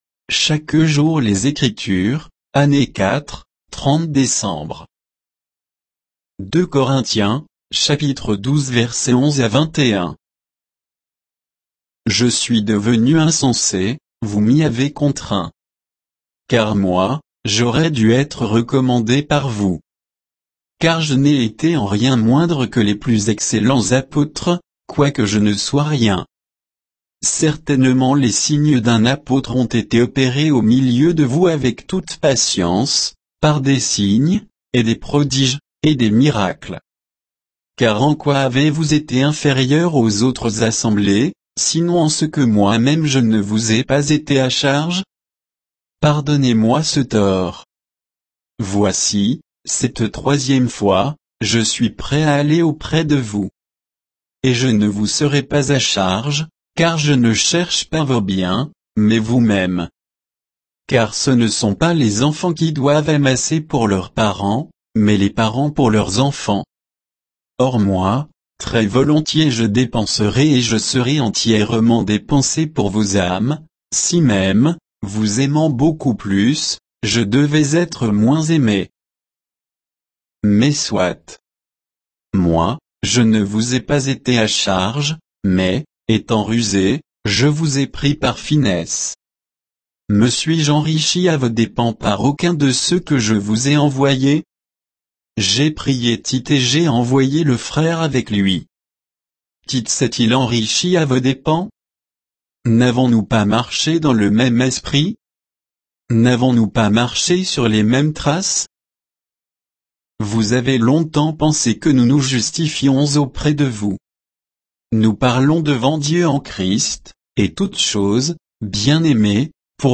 Méditation quoditienne de Chaque jour les Écritures sur 2 Corinthiens 12